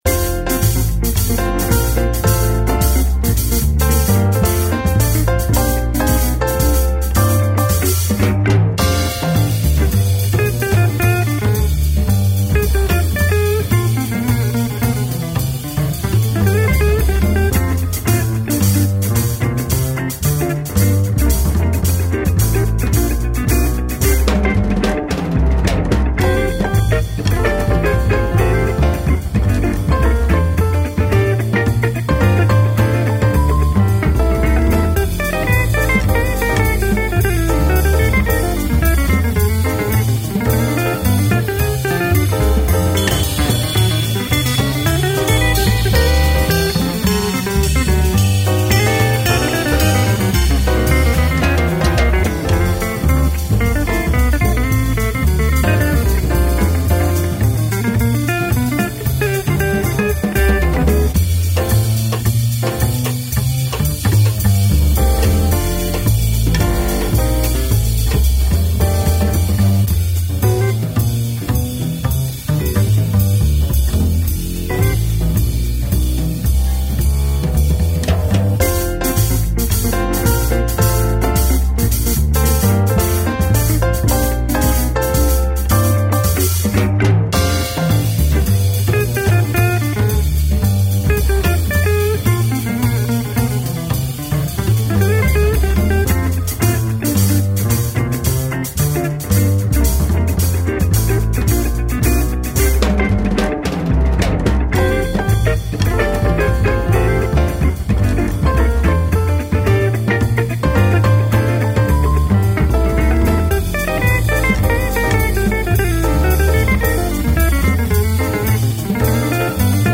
Moderato [110-120] joie - piano - fete - bar - club